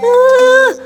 Worms speechbanks
ooff2.wav